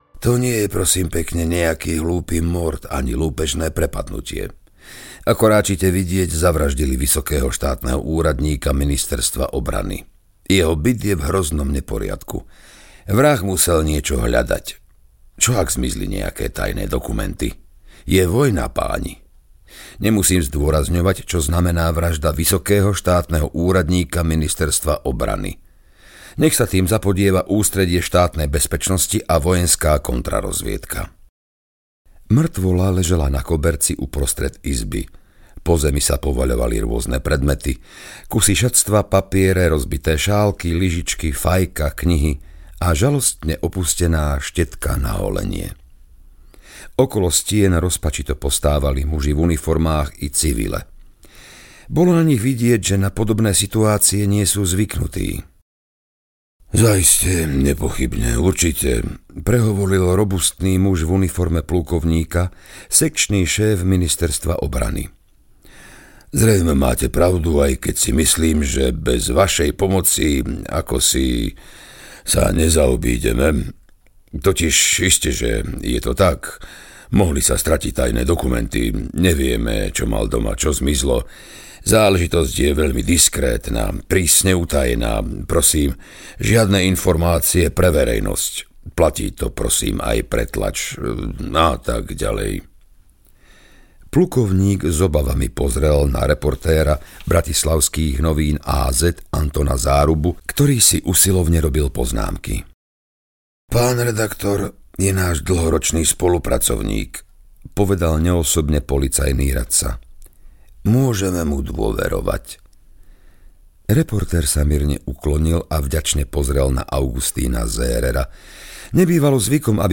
Navždy zbohom audiokniha
Ukázka z knihy